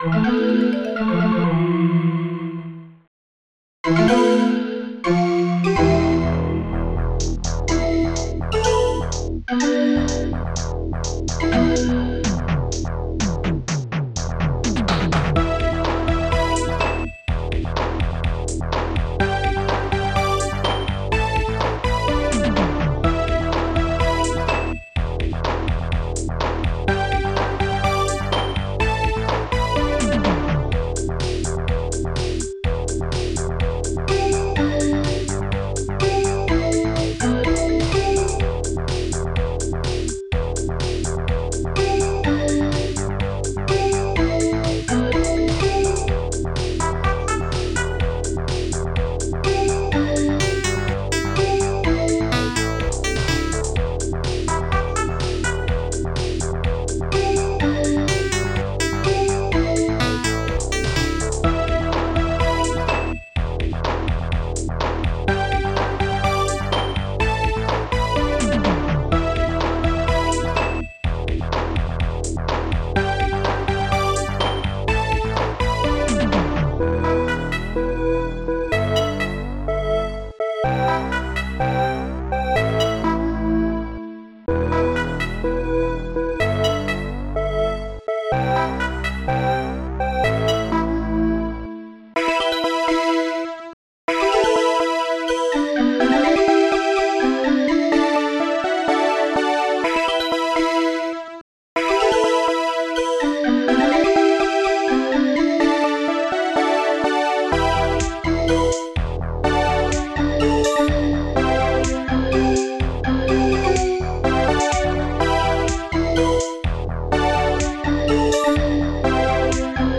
Protracker and family
st-01:bassdrum2
st-01:strings1
st-01:claps1
st-01:hihat2
st-01:marimba
st-01:steinway